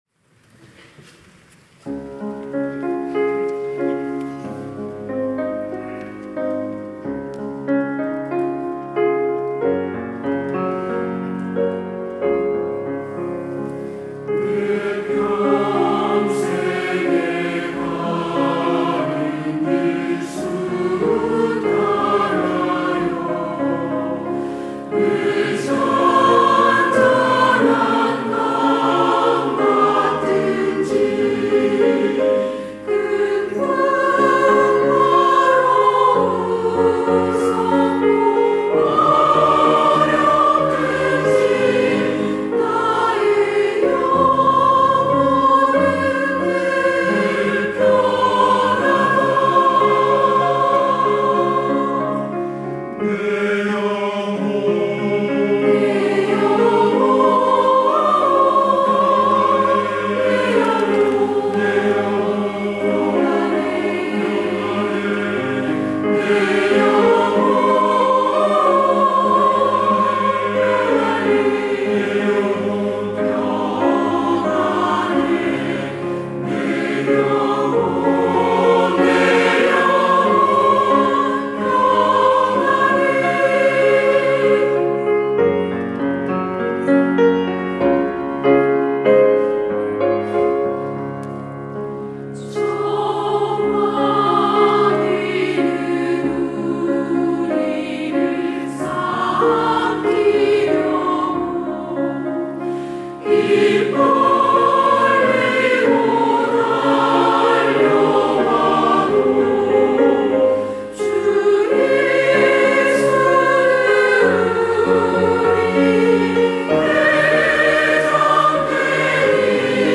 시온(주일1부) - 내 평생에 가는 길
찬양대